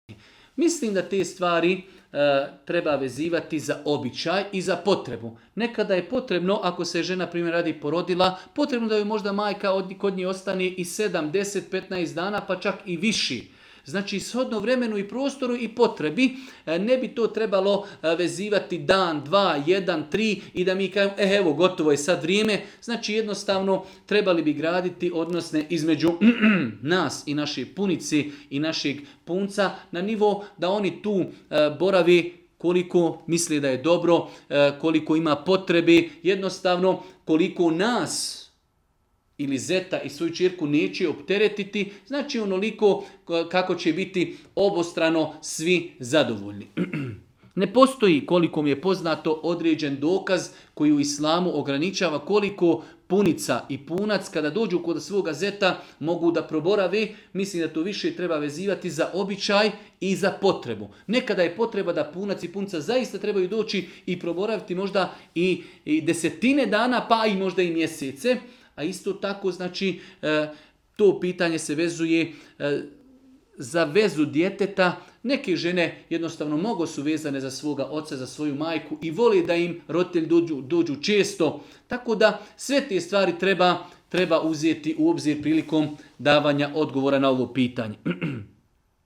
video predavanju ispod